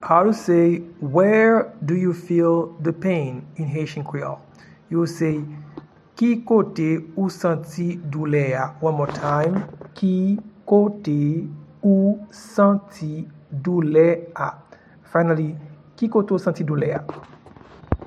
Pronunciation and Transcript:
Where-do-you-feel-the-pain-in-Haitian-Creole-Ki-kote-ou-santi-doule-a.mp3